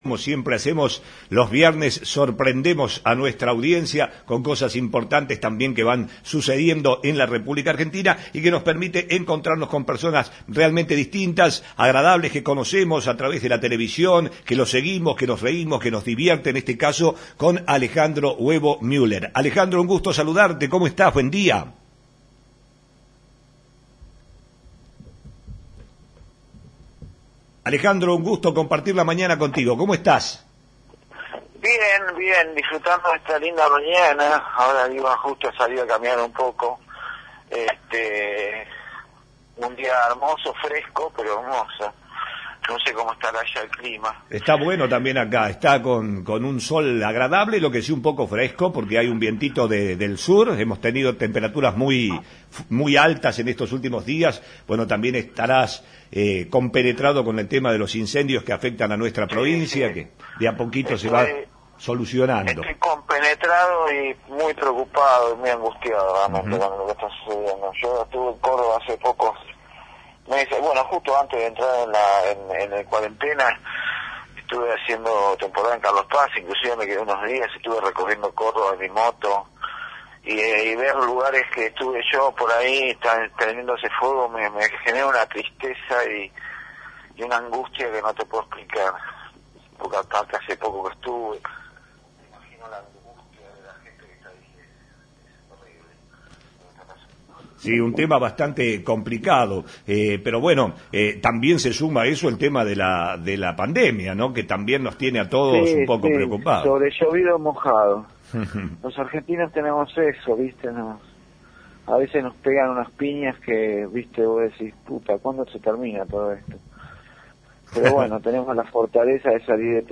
El actor, ganador del Martín Fierro por su papel en «Valientes» se sumó a la mañana de la radio que es parte de tu vida para invitar a la audiencia a ver y sentir teatro vía streaming.